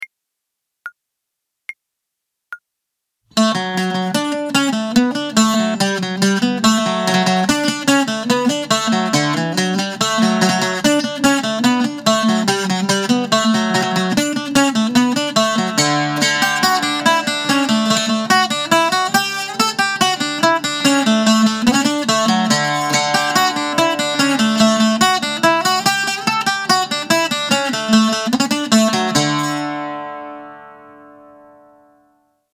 • Stile: irlandese